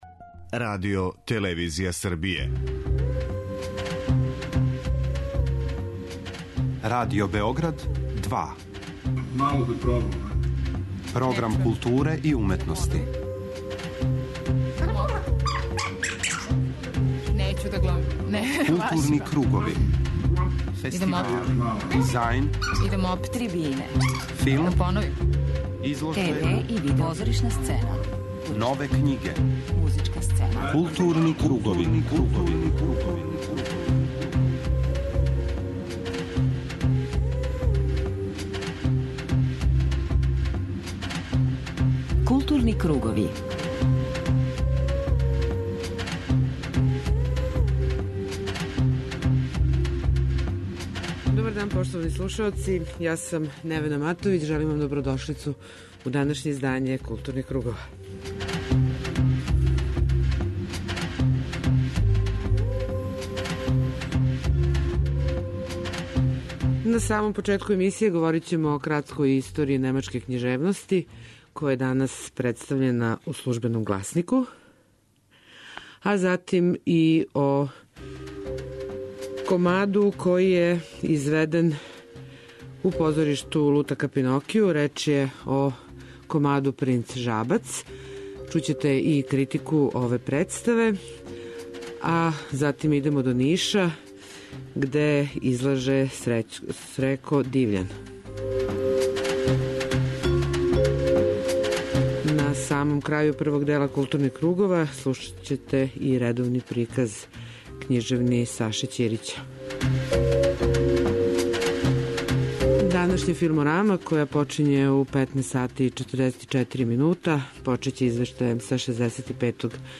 Следи још један разговор снимљен током трајања 11. Фестивала европског дугометражног документарног филма "Седам величанствених", који је је затворен пројекциом филма Правила игре, Клодин Бори и Патриса Шањара.